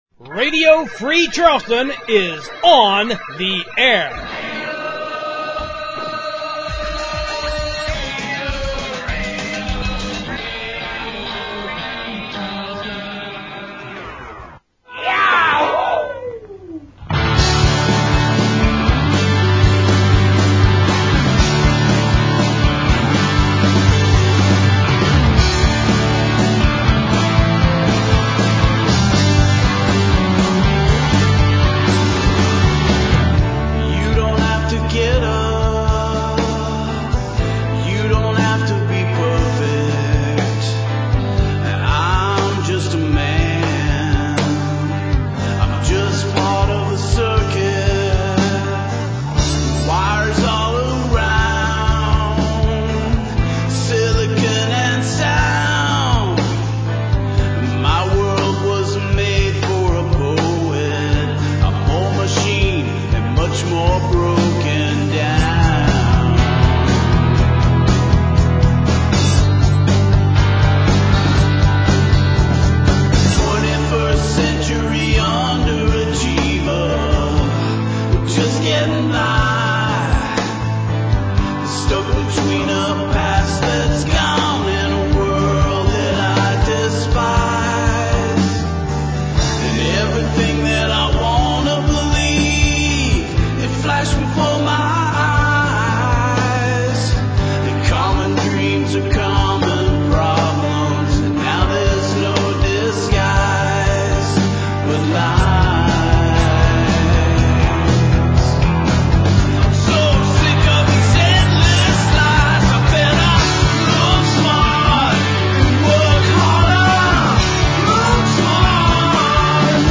Radio Free Charleston opens with an all-new hour that’s loaded with great new music, then we bring you an encore of the very first episode of Radio Free Charleston International, from January 2016.